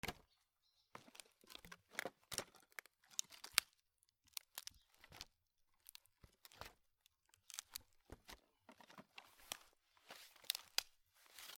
古びた木片 物音
/ M｜他分類 / L01 ｜小道具 /
『ゴソゴソ』